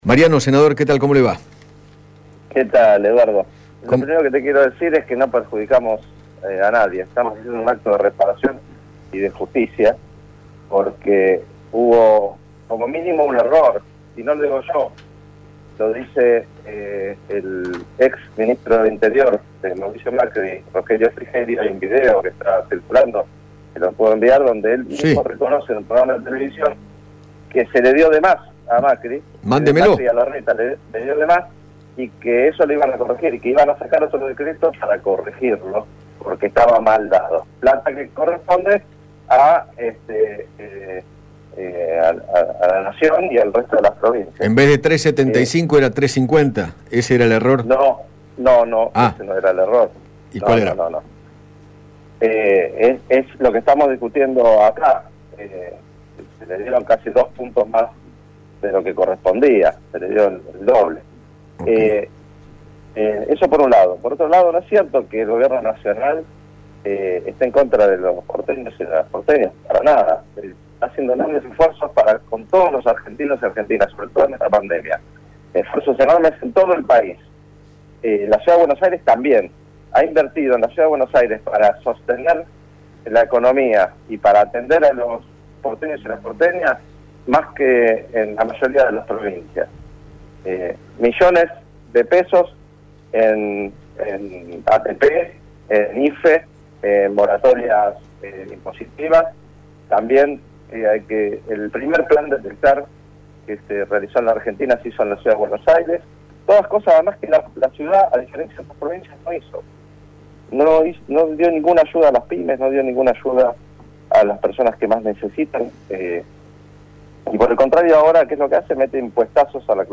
Mariano Recalde, Senador Nacional por la Ciudad de Buenos Aires, dialogó con Eduardo Feinmann sobre el recorte en la coparticipación de la Ciudad, aprobado ayer por la Cámara de Diputados, y en apoyo a la iniciativa sostuvo que “en el gobierno de Macri le dieron casi dos puntos más de lo que correspondía”.